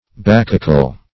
Search Result for " bacchical" : The Collaborative International Dictionary of English v.0.48: Bacchic \Bac"chic\, Bacchical \Bac"chic*al\, a. [L. Bacchicus, Gr.